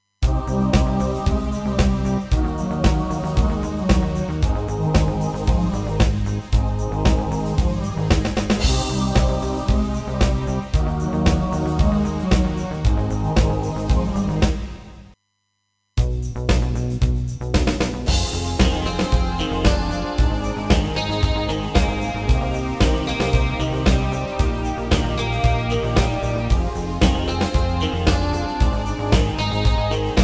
Rubrika: Pop, rock, beat
Karaoke
HUDEBNÍ PODKLADY V AUDIO A VIDEO SOUBORECH